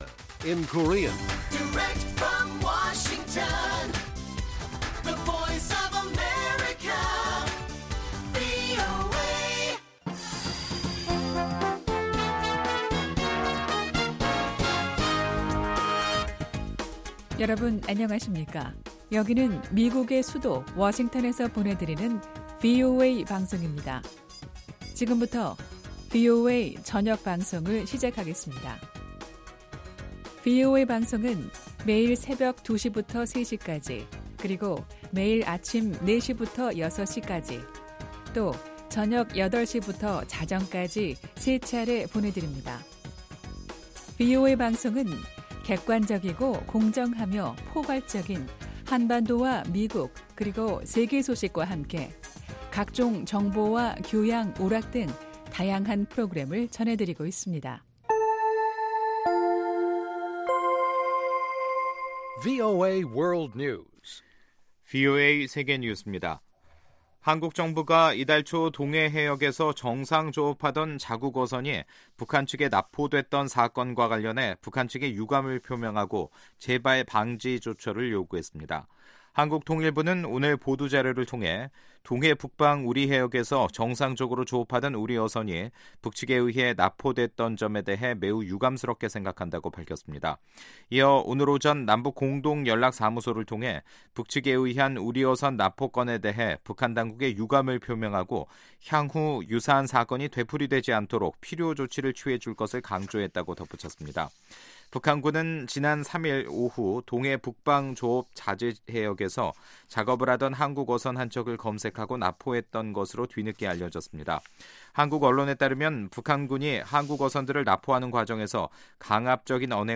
VOA 한국어 간판 뉴스 프로그램 '뉴스 투데이', 2018년 11월 23일 1부 방송입니다. 국제 원자력기구 사무총장은 8월 보고서 발표 이후에도 북한 영변에서 움직임이 관측돼왔다며 사찰이 이뤄져야 이 활동들의 본질과 목적을 확인 할 수 있다고 밝혔습니다. 머지 않아 회기 종료를 앞두고 있는 미국 의회는 각종 북한관련 법안과 결의안을 연내 처리하기 어려울 것으로 보입니다.